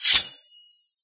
Sword5.ogg